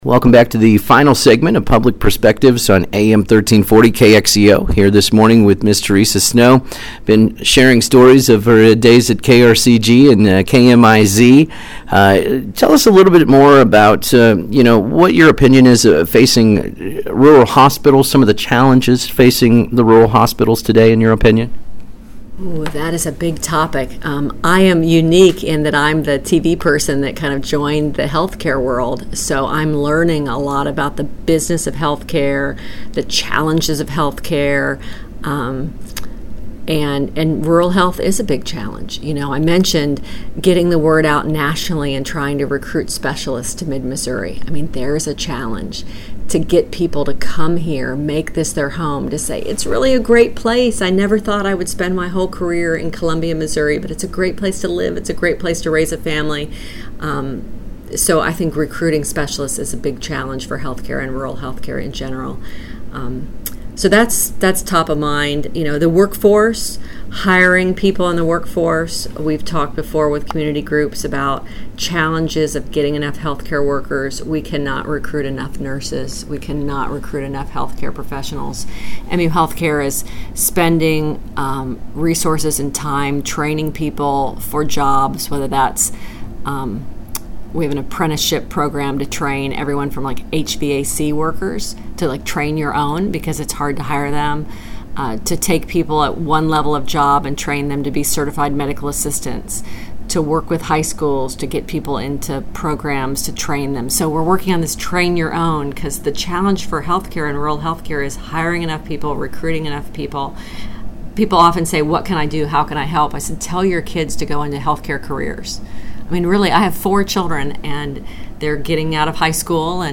Here is the complete interview.